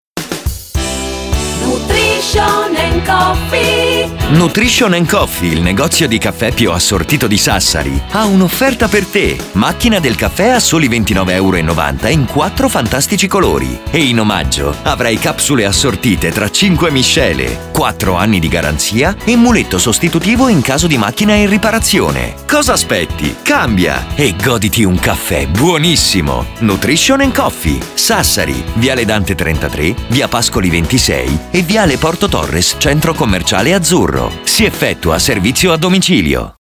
Spot macchine caffè